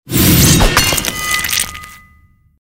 Sonidos: Especiales
Sonidos: Fx web